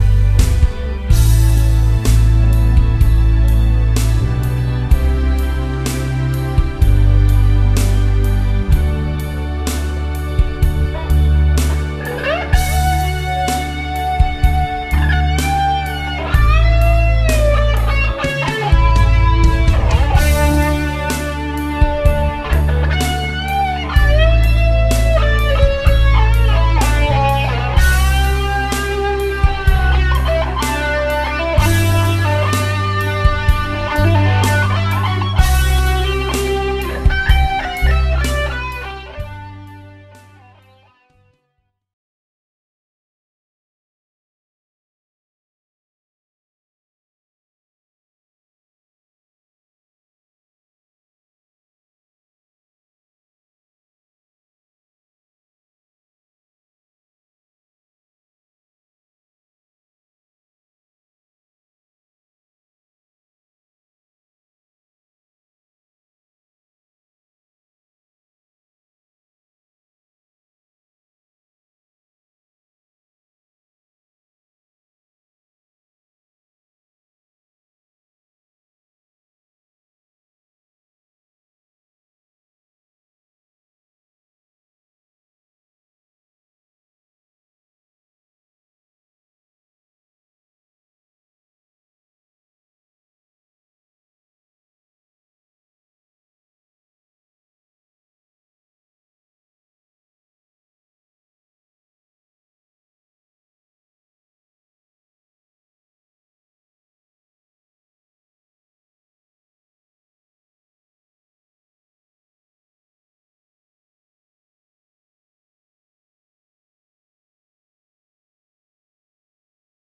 It really gets a nice sharp bite to the notes.
You certainly have the tone down.